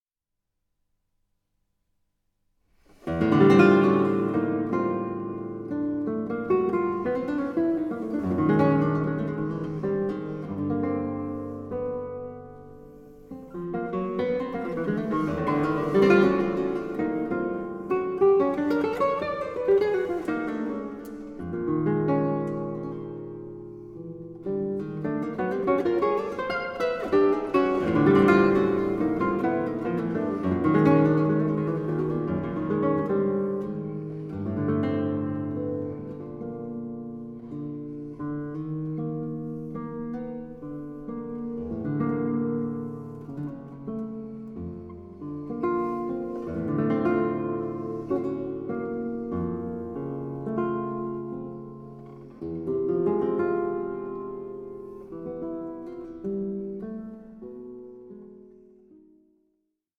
SOLO GUITAR MASTERPIECE
Guitarist